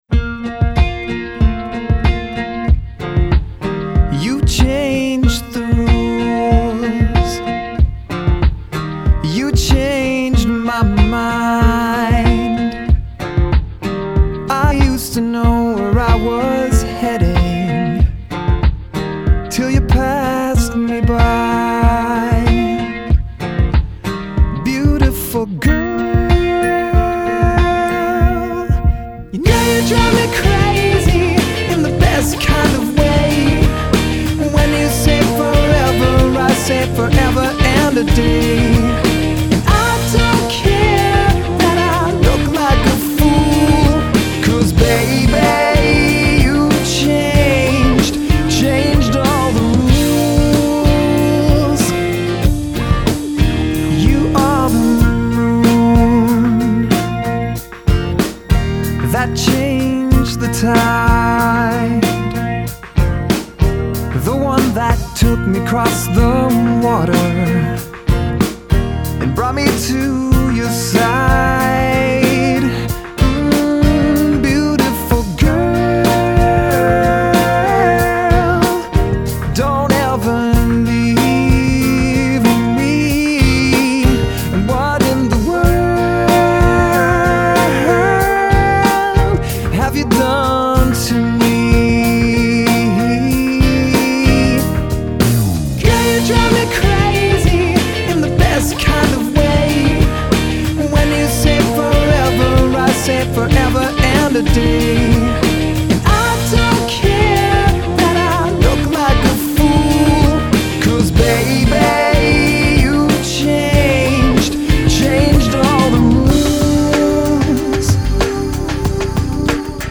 Canadian pop/rock